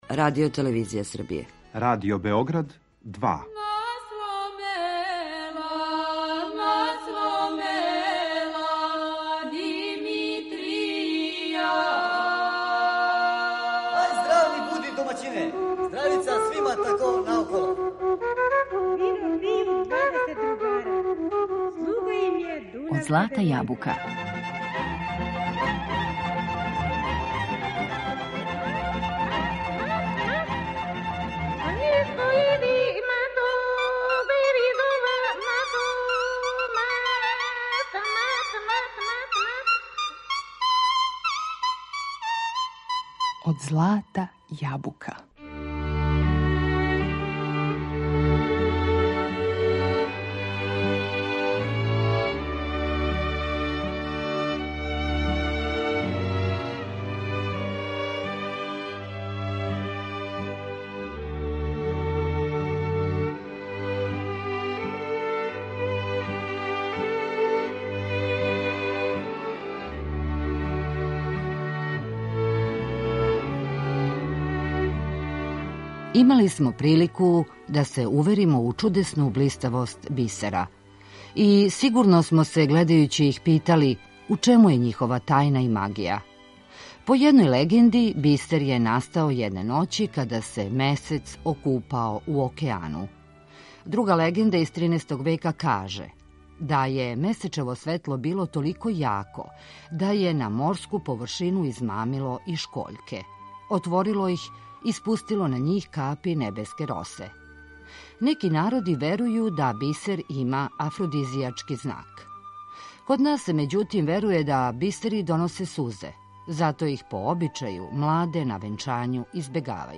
Danas u emisiji Od zlata jabuka govorimo o Ohridu i nadaleko poznatim ohridskim biserima, uz proverene zvuke tradicionalne narodne pesme.